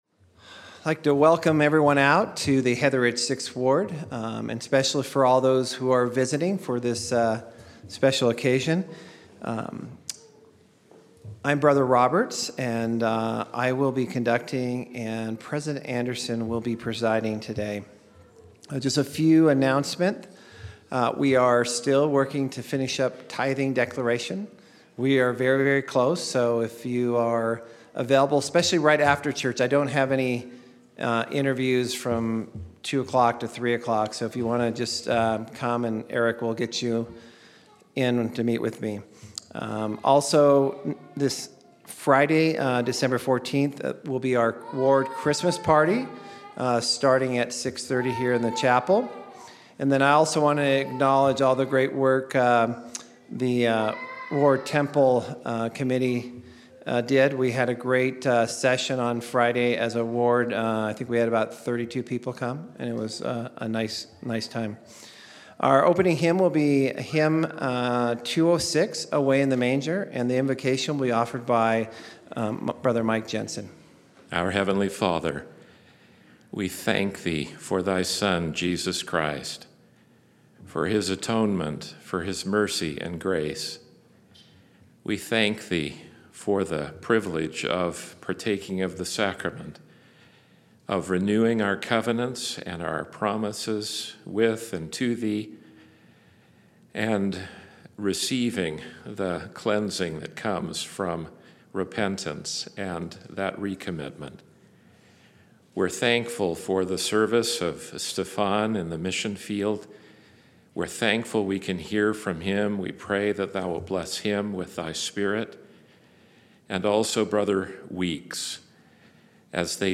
Heatheridge 6th Ward Sacrament Meeting 12/09/18